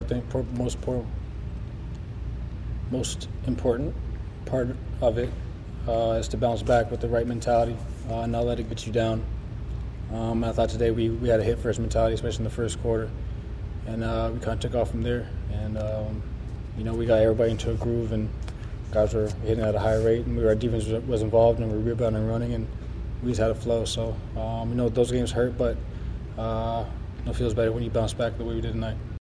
Postgame, Murray spoke on his team’s ability to bounce back from losses.